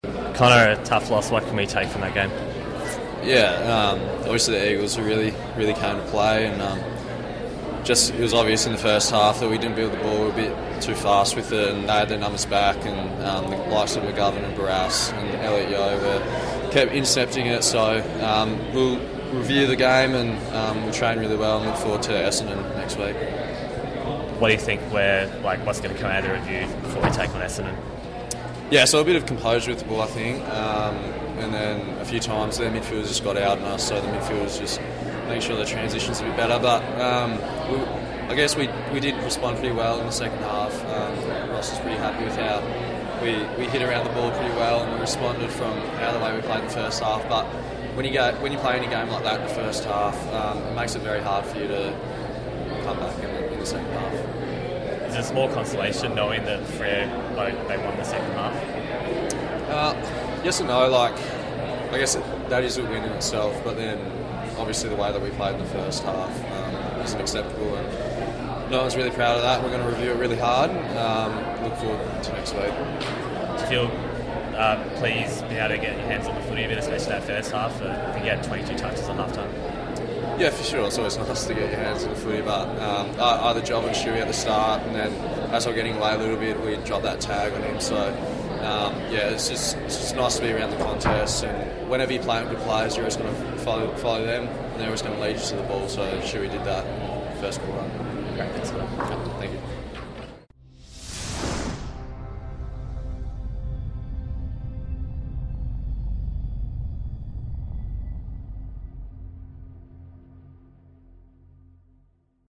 Connor Blakely spoke to the media following the loss to WCE